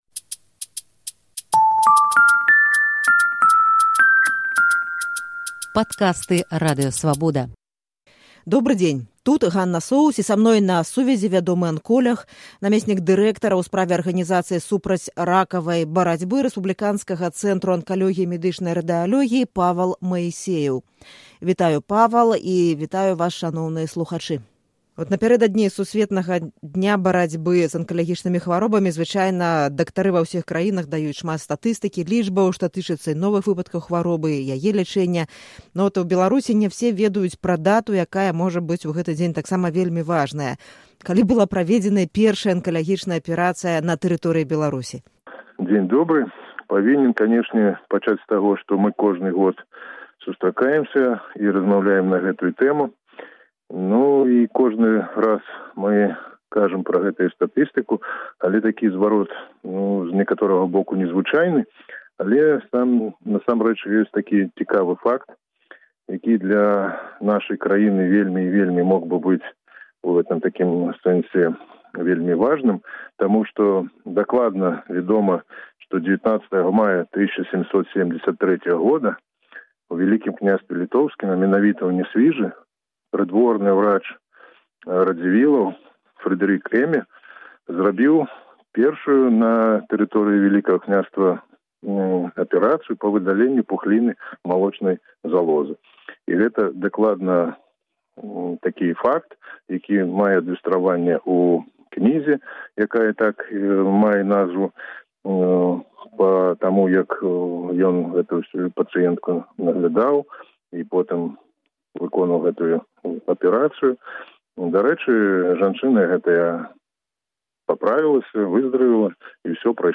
Як вылечыцца ад раку. Размова з анколягам